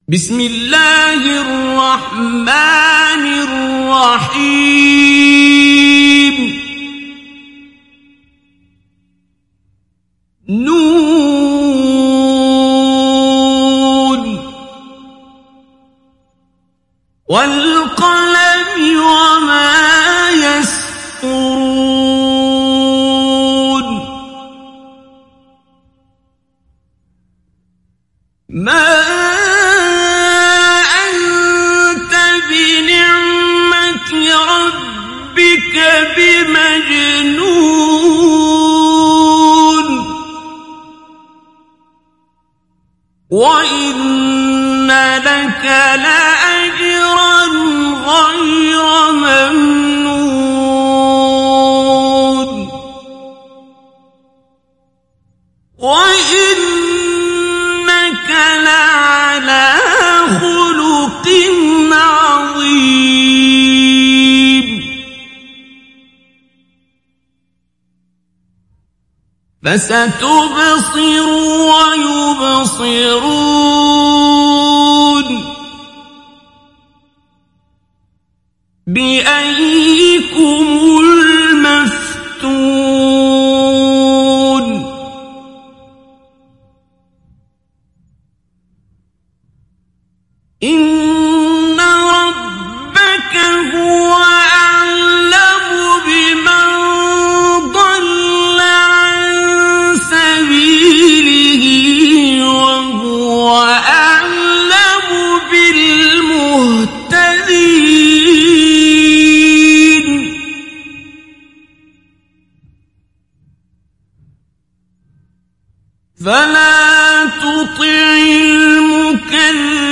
Sourate Al Qalam Télécharger mp3 Abdul Basit Abd Alsamad Mujawwad Riwayat Hafs an Assim, Téléchargez le Coran et écoutez les liens directs complets mp3
Télécharger Sourate Al Qalam Abdul Basit Abd Alsamad Mujawwad